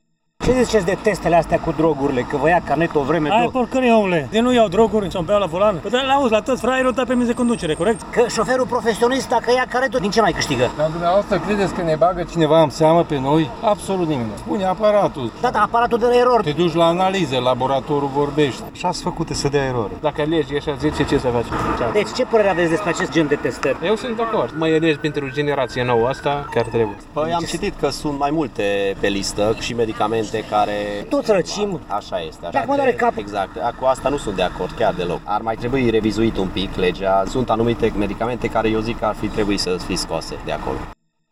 astfel că Ordonanța de guvern ar trebui revizuită Unii dintre conducătorii auto sunt de acord cu aceste testări, care i-ar evidenția pe consumatorii de acool și substanțe interzise, însă spun că nu e normal ca dacă consumă acele medicamente obișnuite care nu se dau pe rețetă, să le fie retras permisul de conducere: